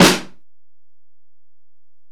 Snare (44).wav